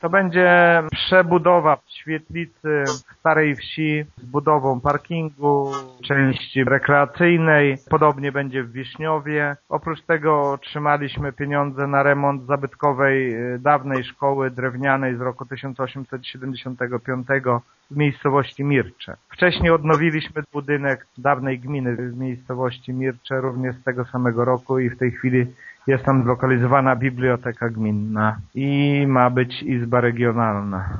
Modernizowane będą budynki w: Górce-Zabłociu, Modryniu, Smoligowie, Tuczapach, Starej Wsi i Wiszniowie” - mówi wójt Lech Szopiński: